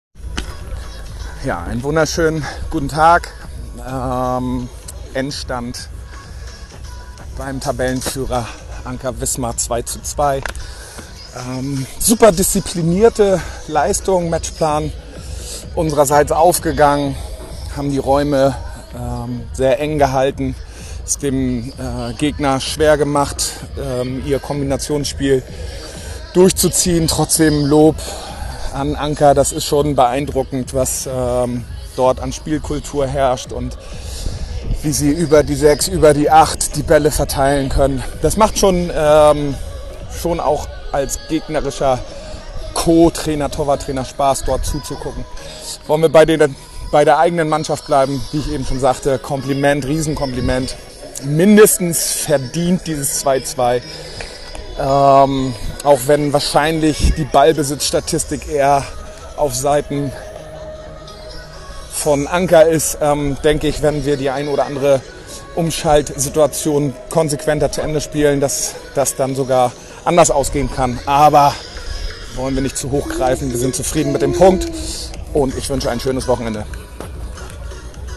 Stimmen zum Spiel